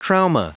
Prononciation du mot trauma en anglais (fichier audio)
Prononciation du mot : trauma